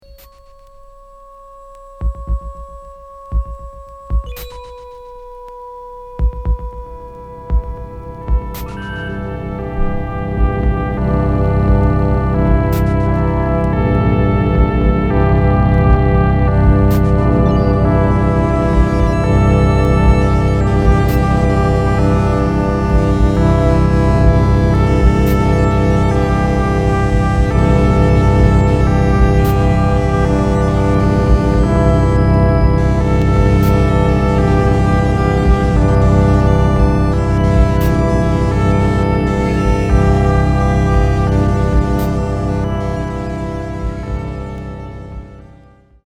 キーワード：電子音　ミニマル